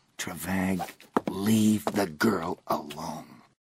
In the audio dramatization of Nightlily: The Lovers' Tale
LeaveTheGirlAlone.ogg